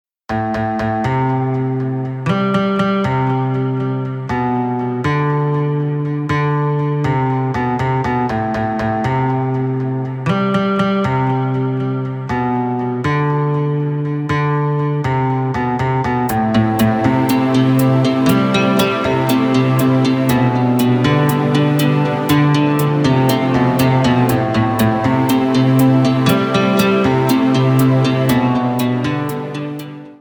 Ремикс
без слов